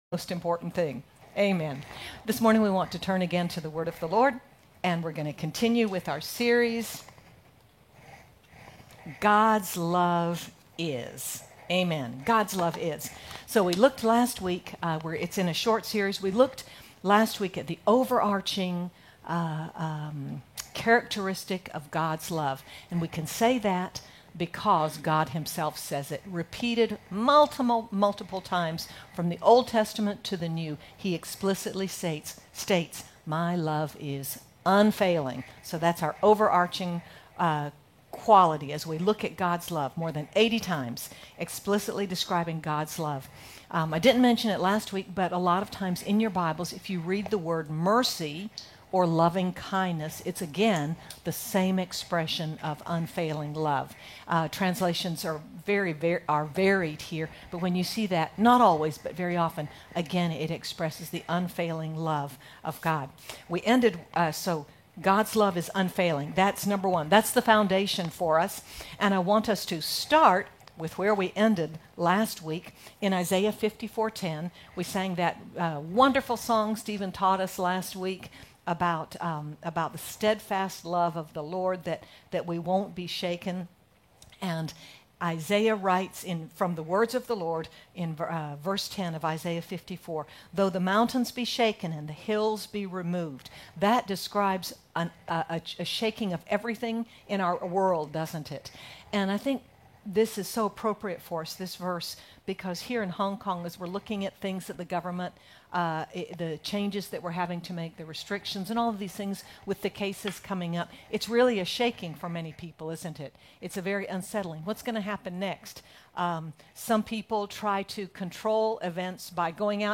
Find rest for your soul and victory in your life knowing you are loved and accepted by God no matter what! Sermon by